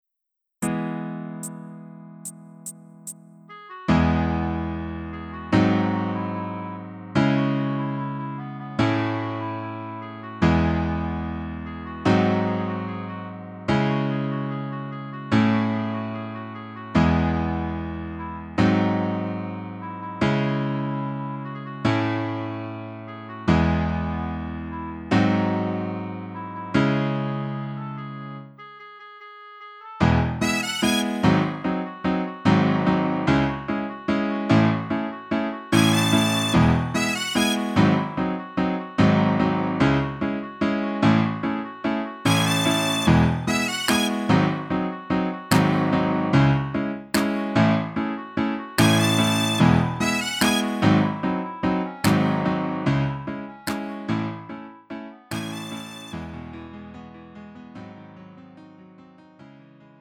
음정 -1키
장르 구분 Lite MR